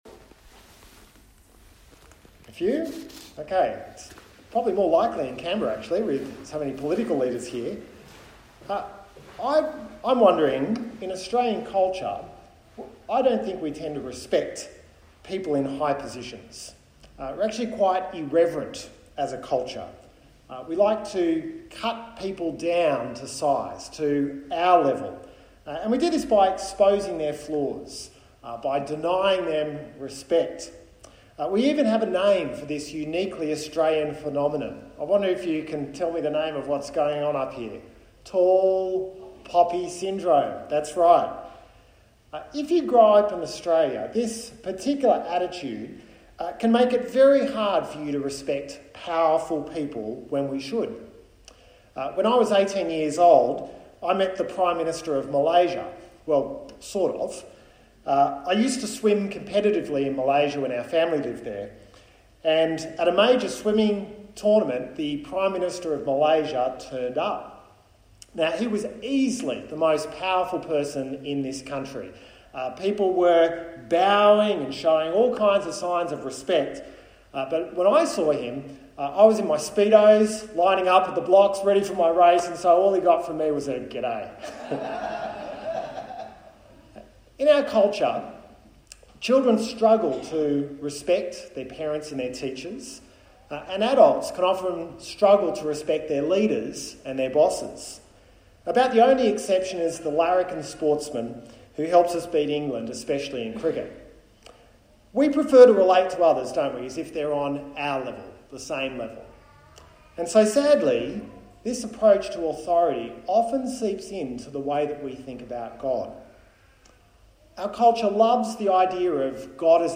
A sermon from the book of Exodus